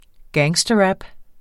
Udtale [ ˈgæːŋsdʌˌɹab ]